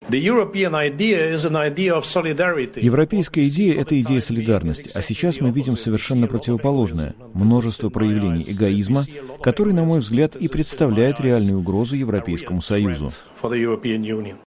Говорит верховный представитель ЕС по внешней политике Федерика Могерини